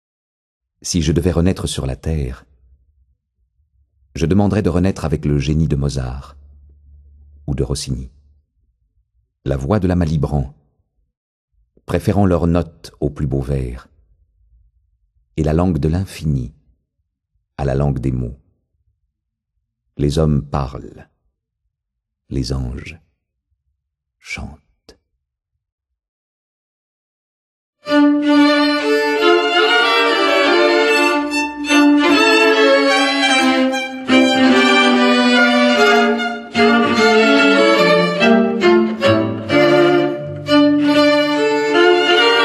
0% Extrait gratuit Notes et lettres de Mozart de Wolfgang Amadeus Mozart Éditeur : Autrement Dit Paru le : 2009 Cette anthologie mêle habilement les musiques du plus grand génie musical de tous les temps et sa correspondance parfois drôle, impertinente, primesautière, parfois tragique lorsqu'il évoque la mort de sa maman...